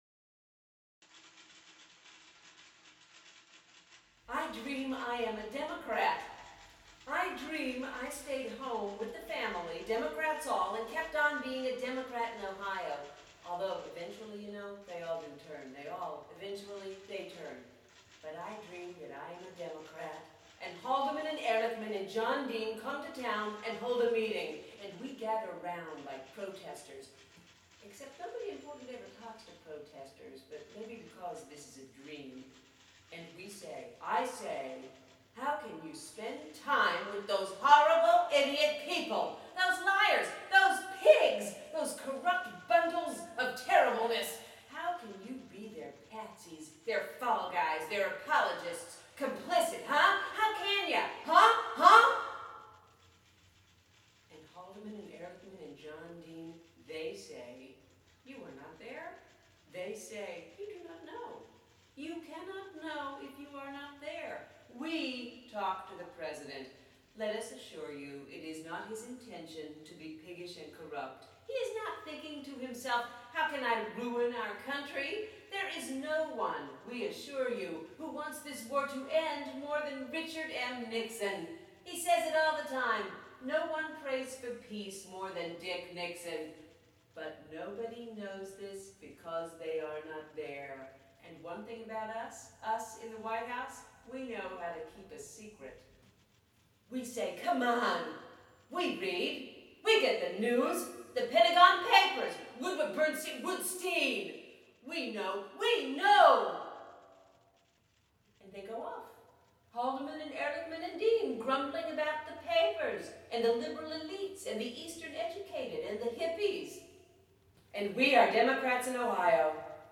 An excerpt from STRETCH (a fantasia)